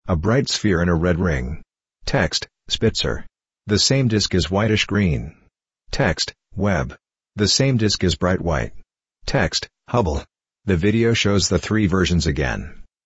Audio Description.mp3